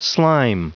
Prononciation du mot slime en anglais (fichier audio)
Prononciation du mot : slime